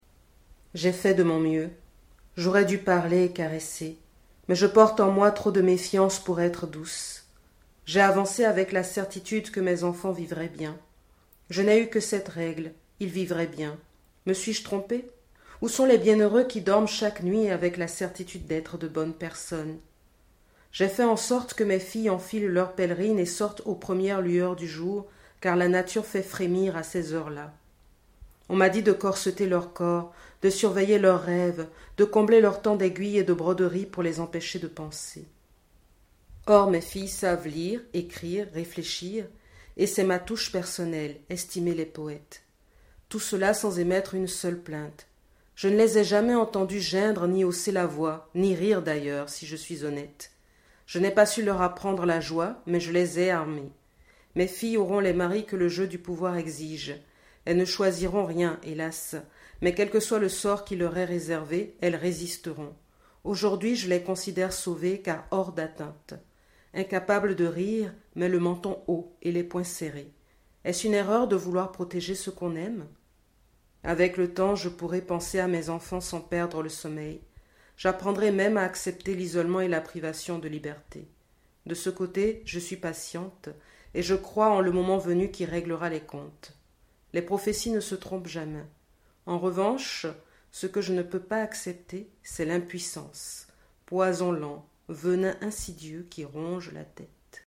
Plutôt que de les reproduire, je vous les lis :